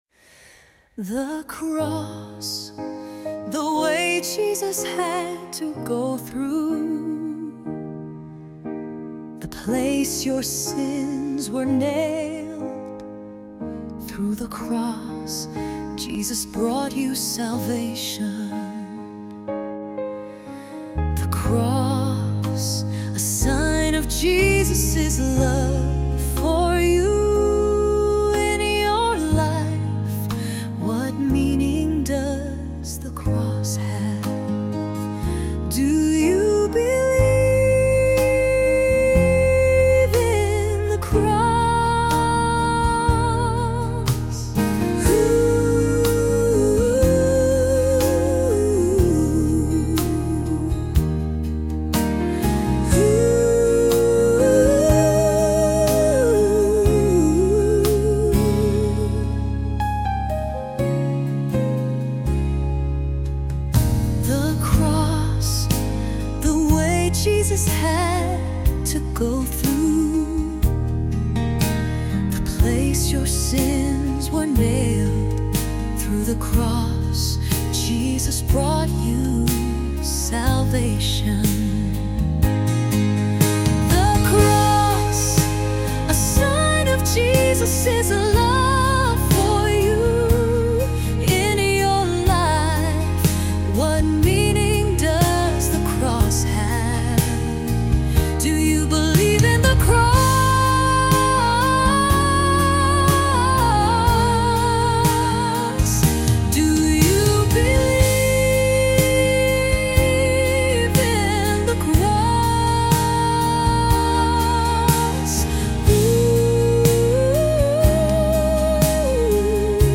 Listen to AI generated music